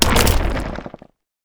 creaking_attack3.ogg